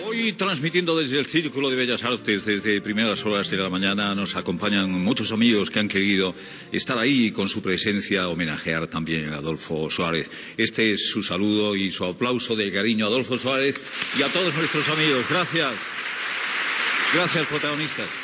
Especial des del Círculo de Bellas Artes de Madrid en homenatge a l'ex president del govern espanyol Adolfo Suárez.
Info-entreteniment